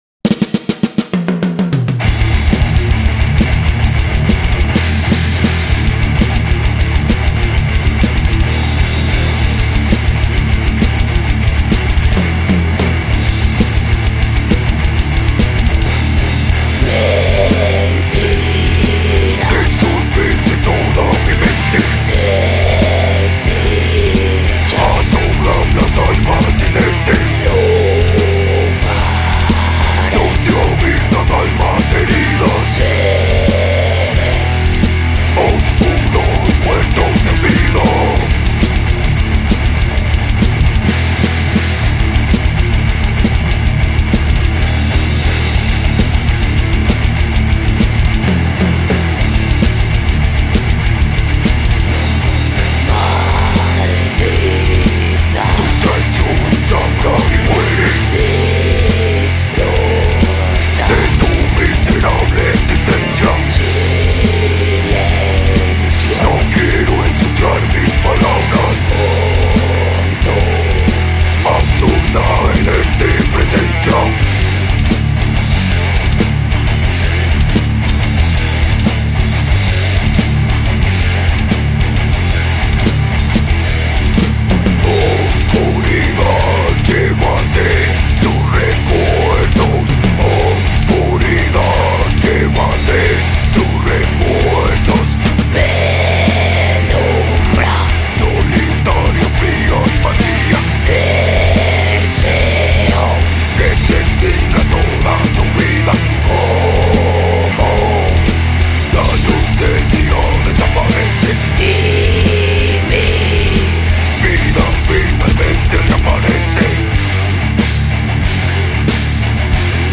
Guitarra y Voz
Bajo
Melodic death metal